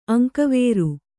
♪ aŋkavēṛu